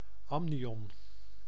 Ääntäminen
IPA : /ˈæmnɪɒn/ US : IPA : /ˈæmnɪɑːn/